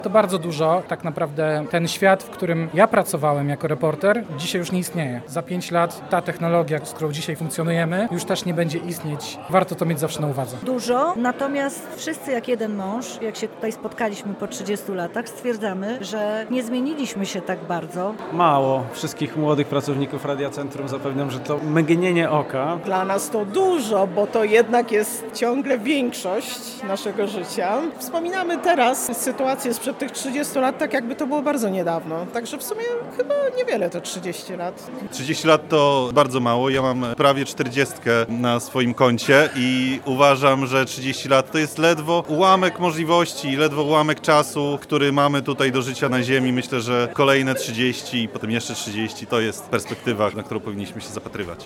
Uczciliśmy tę okazję uroczystą Galą Jubileuszową.
sonda jubileusz